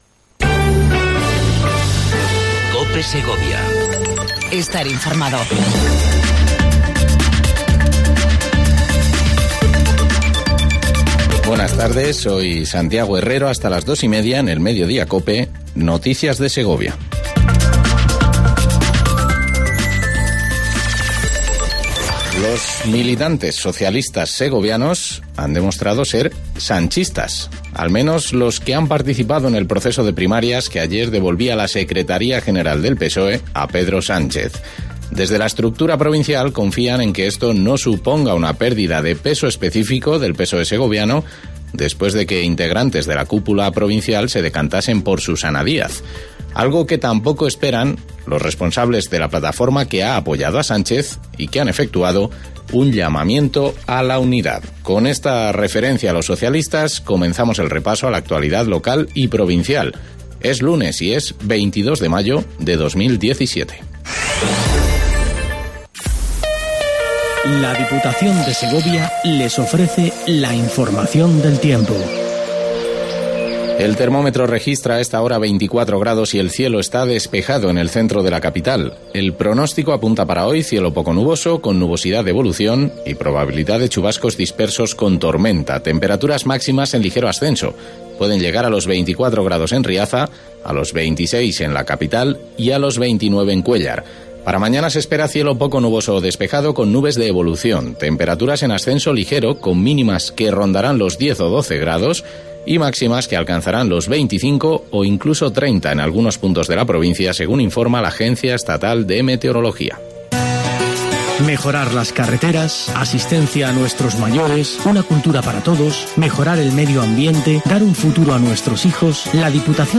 INFORMATIVO MEDIODIA COPE EN SEGOVIA 22 05 17